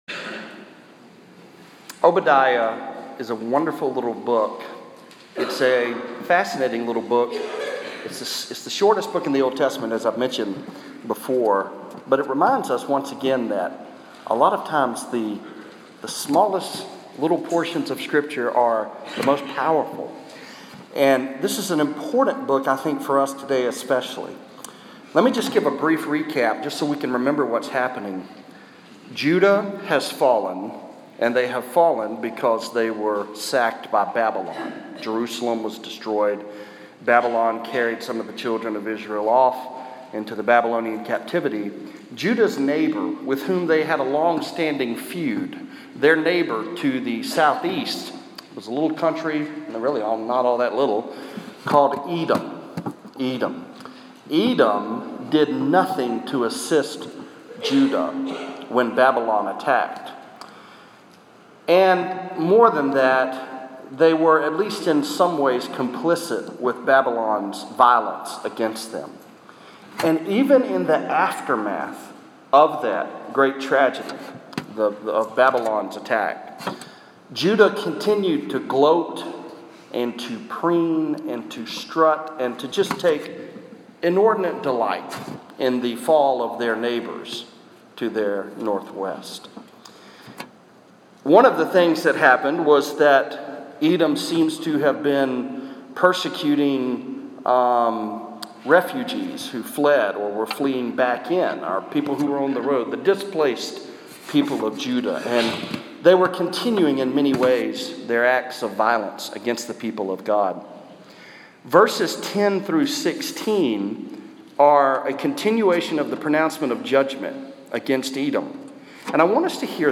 Obadiah 10-16 (Preached on April 9, 2017, at Central Baptist Church, North Little Rock, AR)